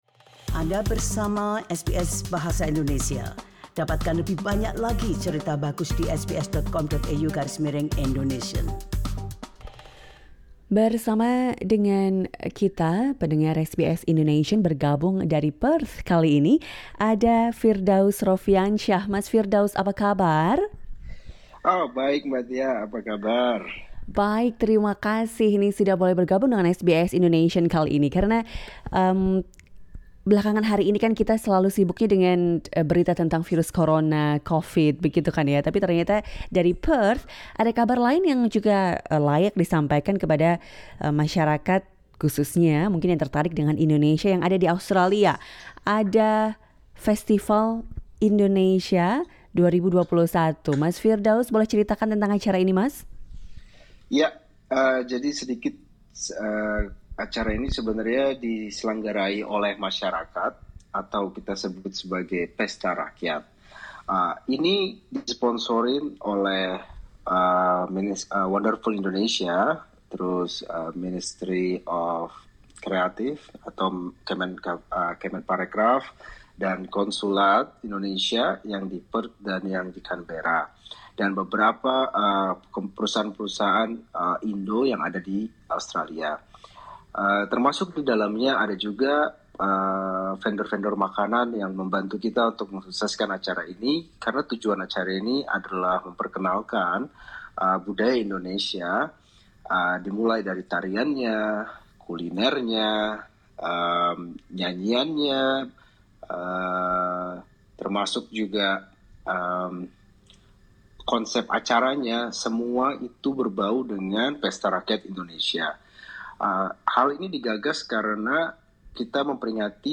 Dengarkan wawancara selengkapnya di podcast SBS Indonesian.